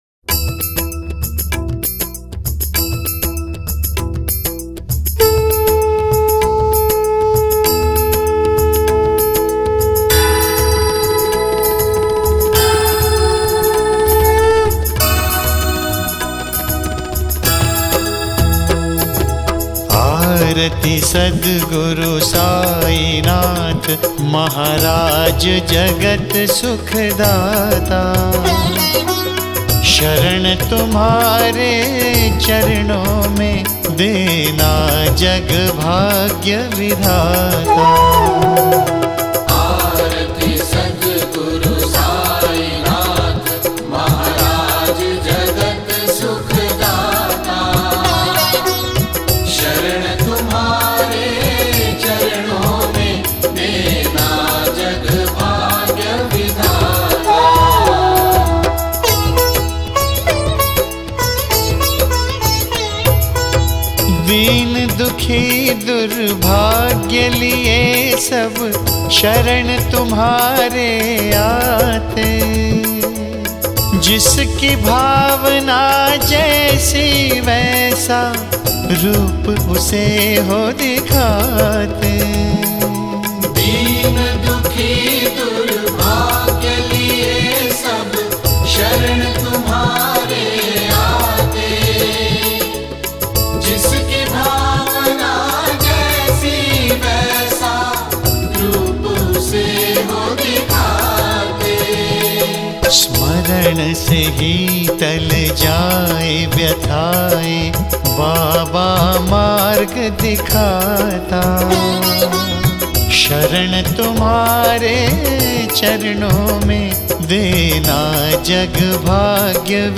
These are very pleasent and mind blowing songs.